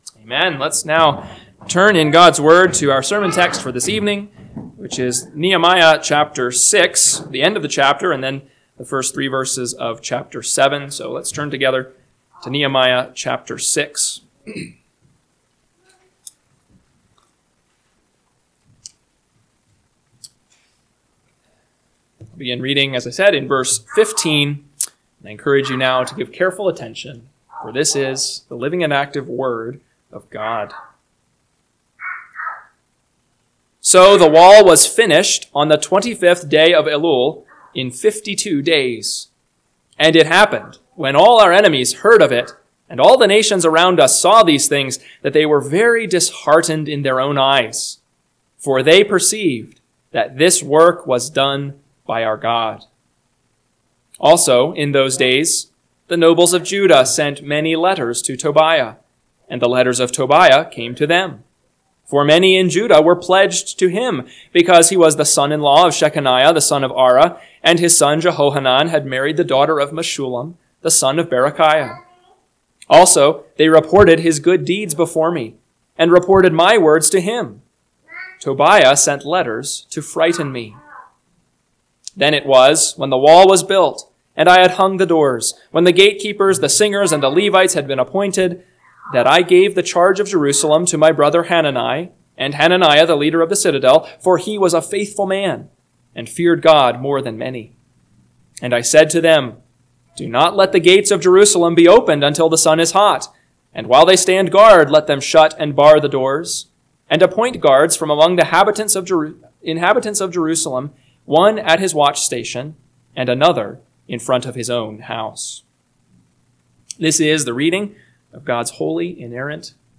PM Sermon – 9/14/2025 – Nehemiah 6:15-7:3 – Northwoods Sermons